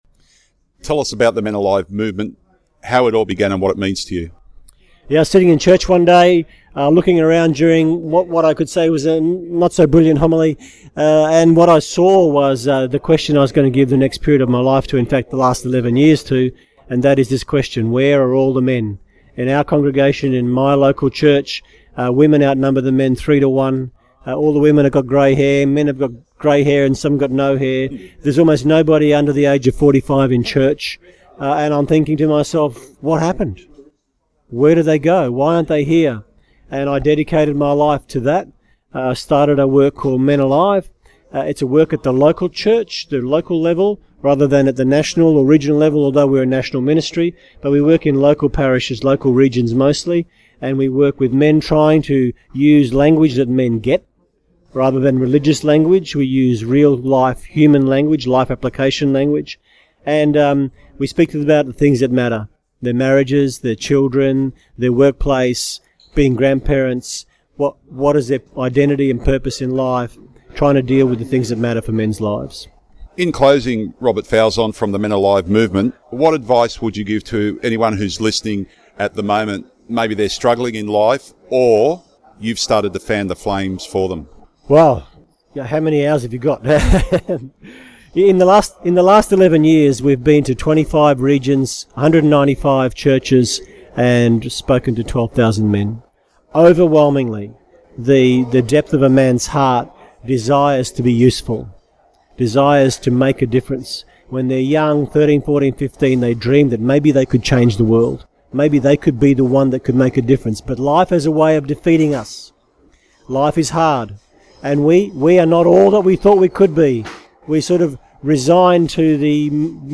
There are also regular interviews highlighting the good things being done in the Church and its agencies.